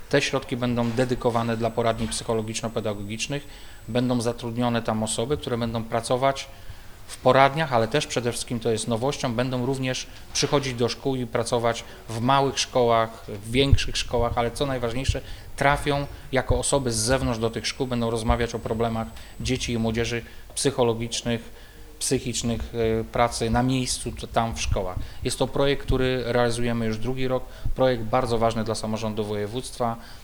O projekcie mówi Wojciech Bochnak, wicemarszałek Województwa Dolnośląskiego.